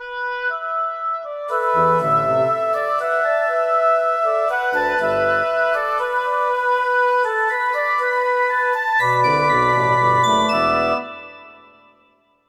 Sección de madera (orquesta sinfónica) Breve pieza ejemplo.
aerófono
clarinete
corno
fagot
flauta
oboe